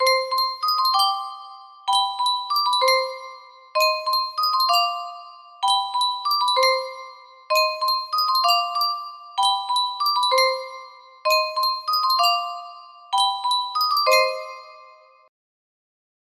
Type Full range 60
BPM 96